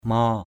/mɔ:/ (d.) xô, gàu = seau. bucket. maow aia _m<w a`% xô nước, gàu nước = seau pour l’eau. bucket for water. 2.